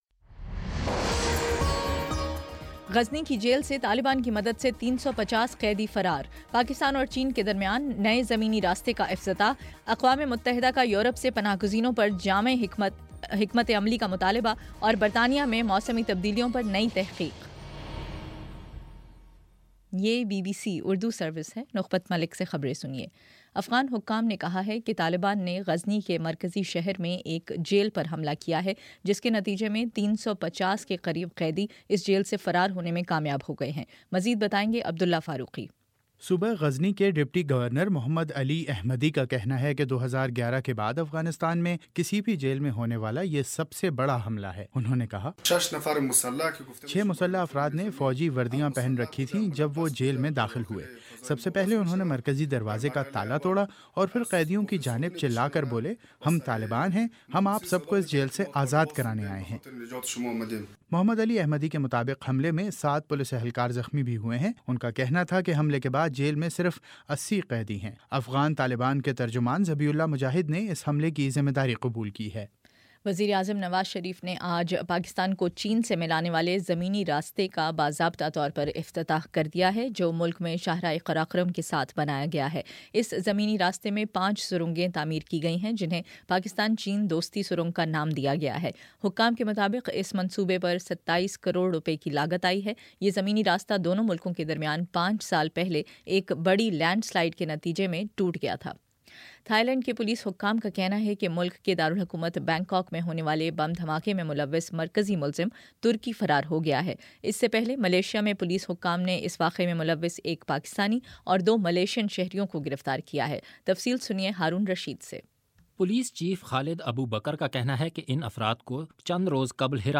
ستمبر14 : شام چھ بجے کا نیوز بُلیٹن